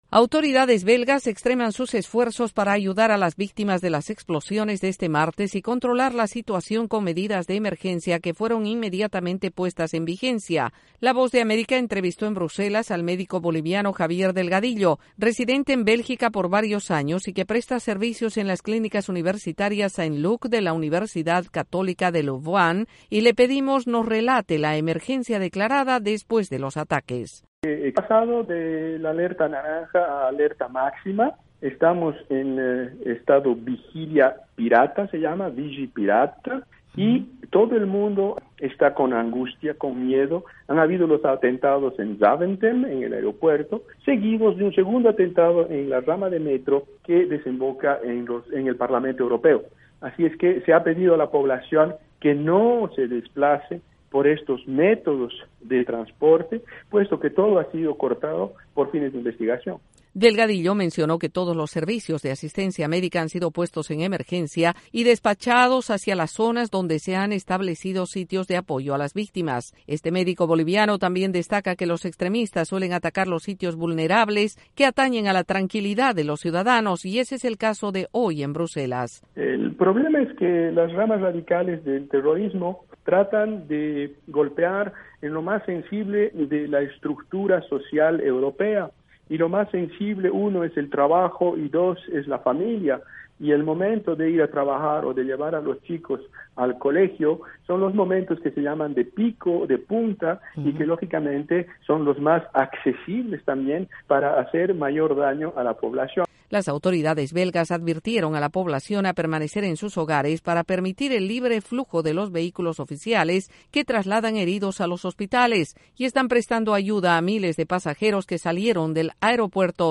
Los belgas viven hoy con alerta máxima y recomendaciones específicas luego de los atentados de hoy y entrevistamos a un médico latino residente en Bruselas.